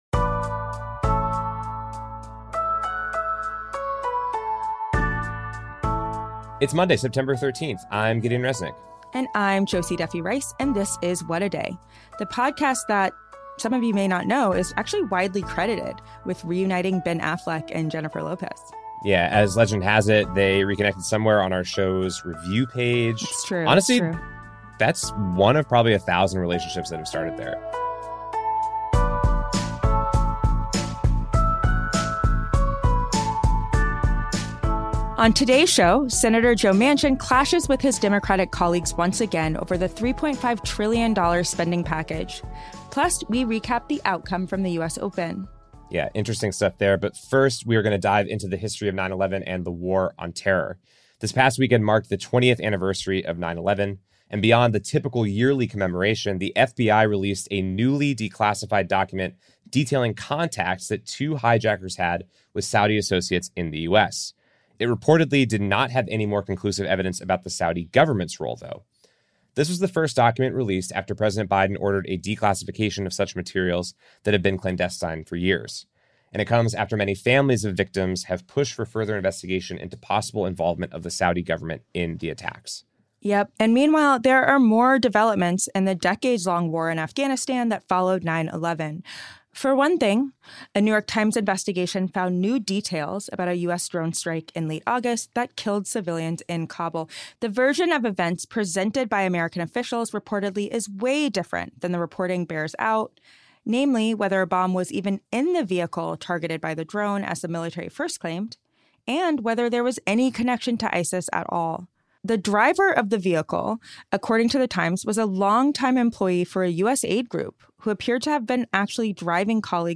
This past weekend marked the 20th anniversary of 9/11, and beyond the typical yearly commemoration, the FBI released a newly declassified document detailing contacts that two hijackers had with Saudi associates in the U.S. Pulitzer Prize-winning journalist, Spencer Ackerman, joins us to discuss the history of 9/11, the war on terror, and the latest developments in Afghanistan.